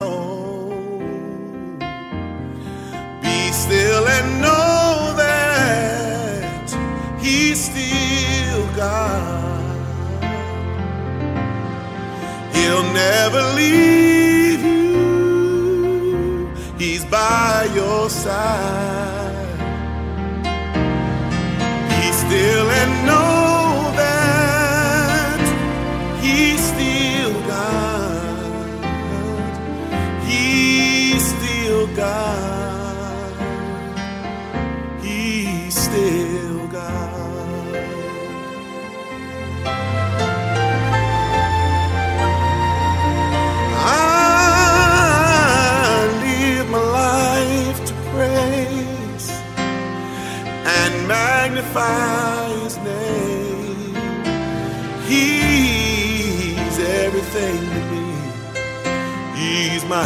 im Stil aktueller Gospelmusik
von herausragenden Sängern und Sängerinnen eingesungen
• Sachgebiet: Praise & Worship